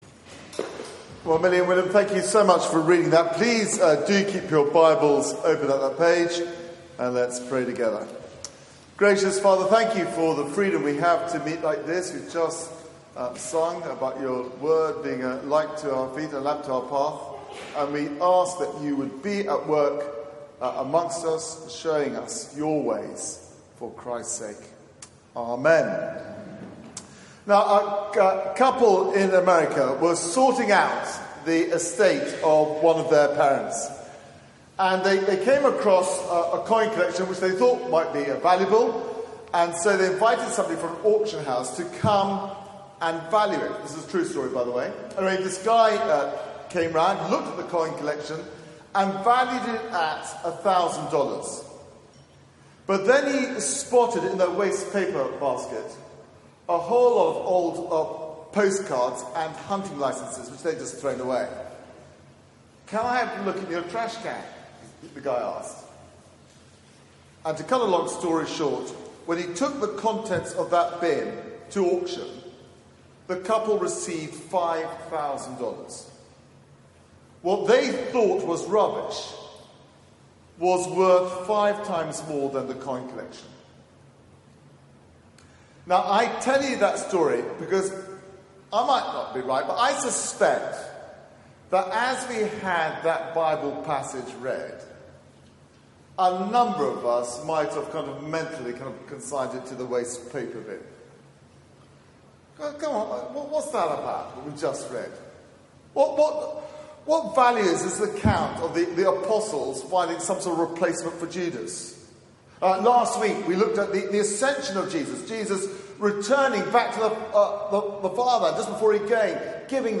Media for 4pm Service on Sun 10th Jun 2018 16:00 Speaker
Sermon